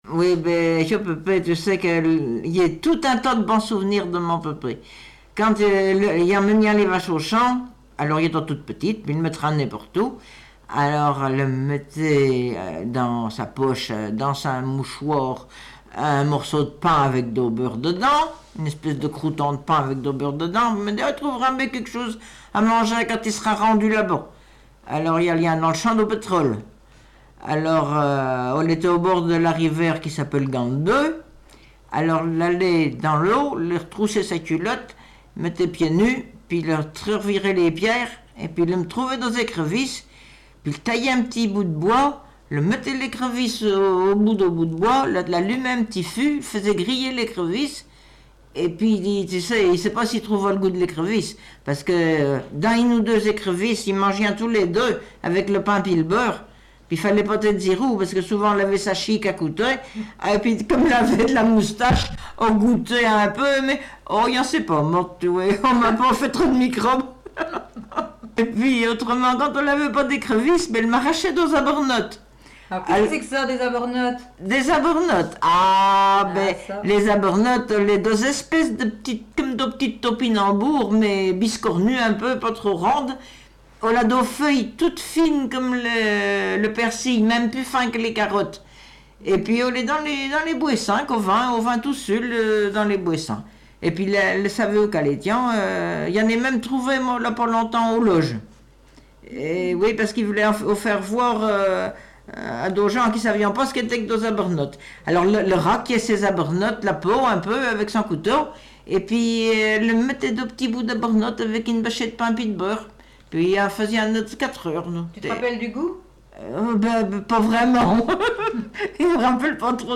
Mémoires et Patrimoines vivants - RaddO est une base de données d'archives iconographiques et sonores.
Langue Patois local
Catégorie Témoignage